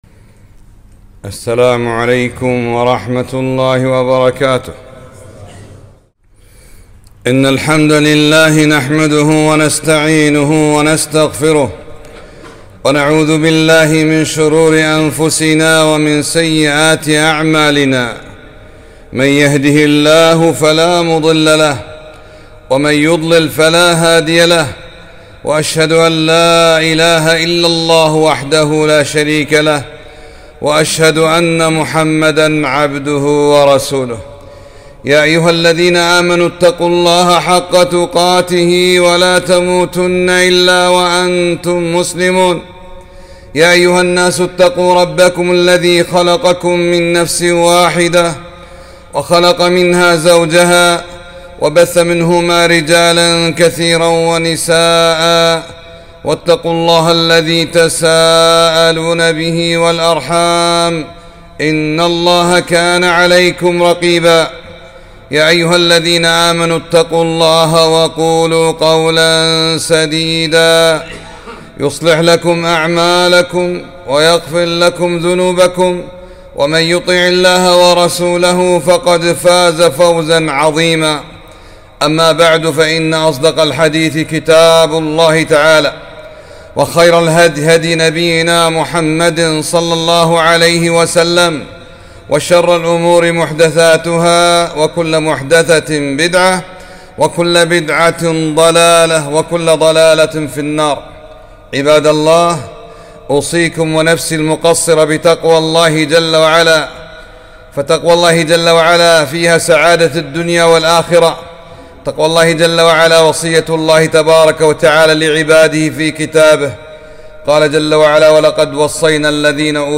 خطبة - احرص على ماينفعك واستعن بالله ولا تعجز